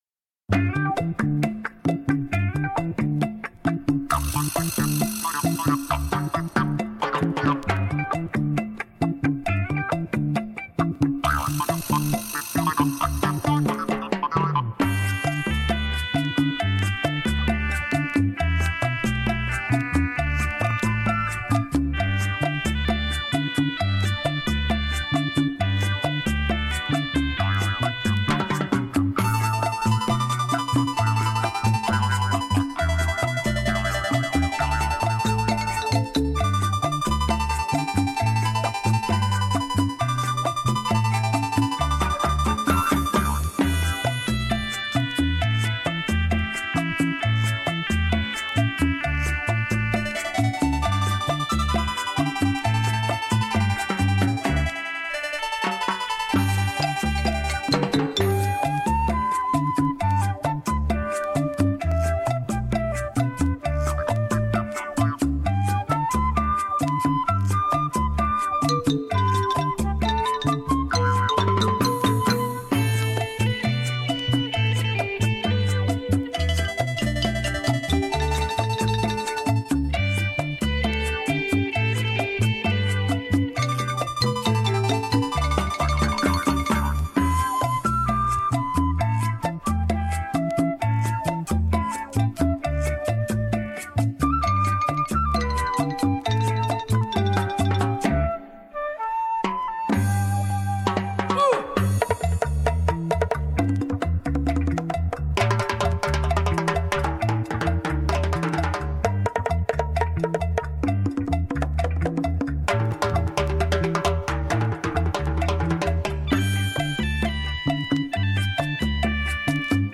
黑胶LP